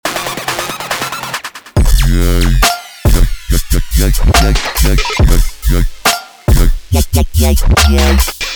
How to Create the “YOI” Dubstep Bass in Massive
If you were immersed in the dubstep scene around the 2008-2011 years, there is no doubt that you’ve heard the infamous YoY or Yoi bass in countless tunes.
yoy-bass-example.mp3